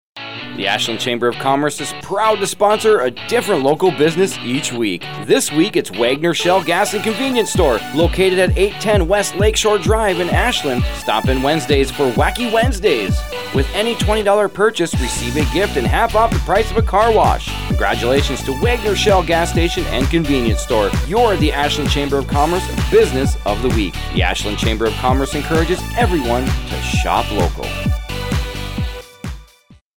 Each week the Ashland Chamber of Commerce highlights a business on Heartland Communications radio station WATW 1400AM. The Chamber draws a name from our membership and the radio station writes a 30-second ad exclusively for that business.